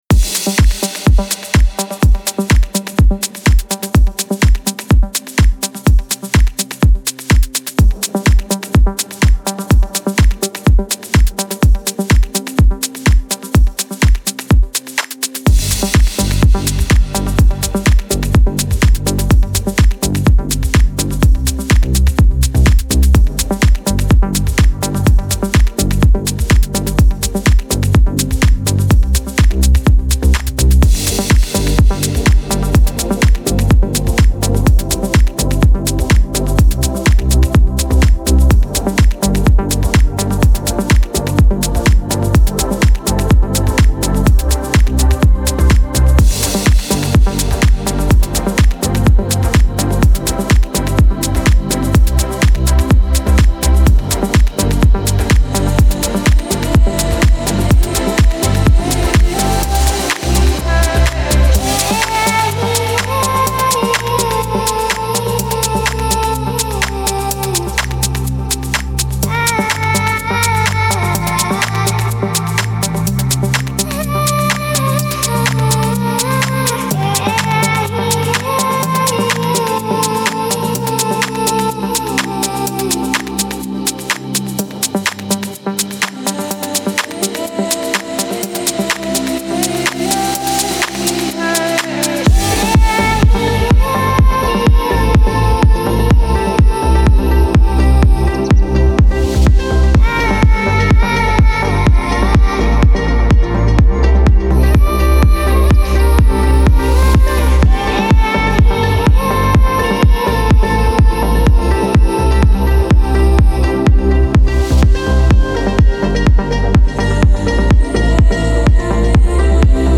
موسیقی بی کلام هاوس
موسیقی بی کلام پر انرژی